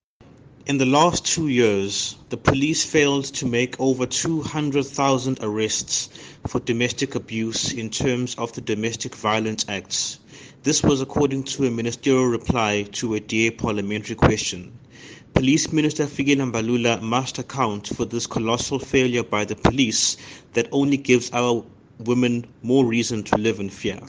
Issued by Zakhele Mbhele MP – DA Shadow Minister of Police
Please find the attached sound bites in and